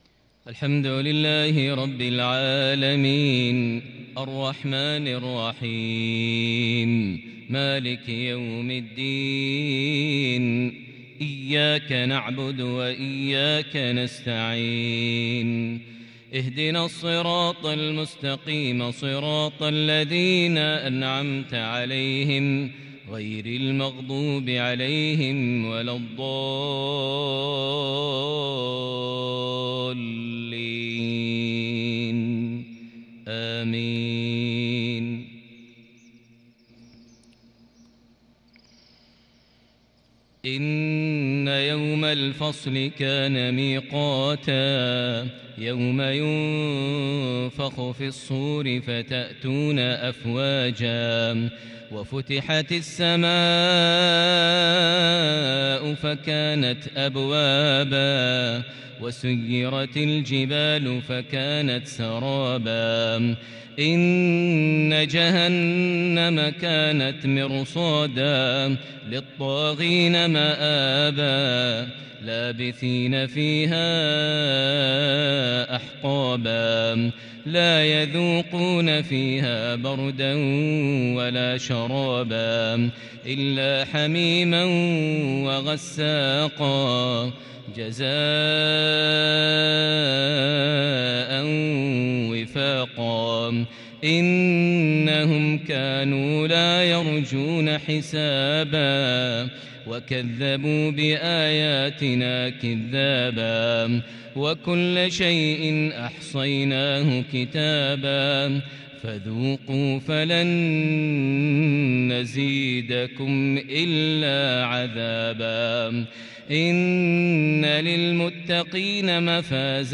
مغربية شجية لخواتيم سورتي النبأ - النازعات | الجمعة 29 ذو القعدة 1442هـ > 1442 هـ > الفروض - تلاوات ماهر المعيقلي